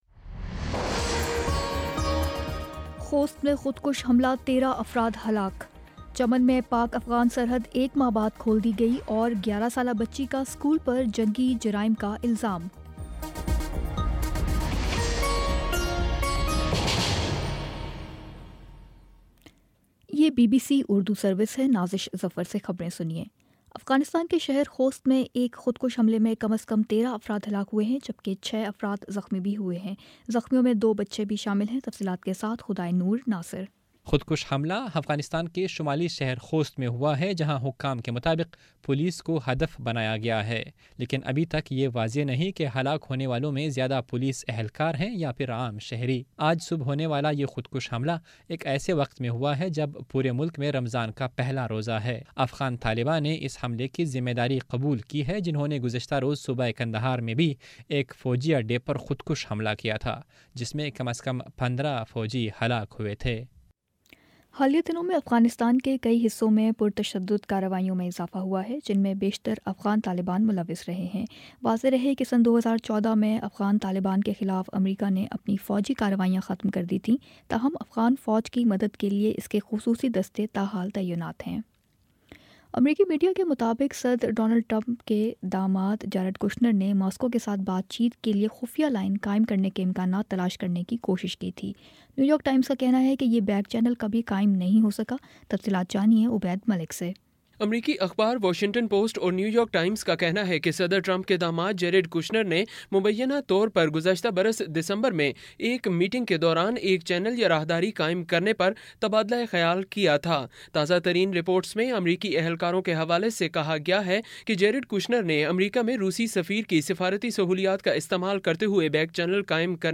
مئی 27 : شام چھ بجے کا نیوز بُلیٹن